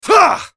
Sound Buttons: Sound Buttons View : Yasuo Spell Effort *-*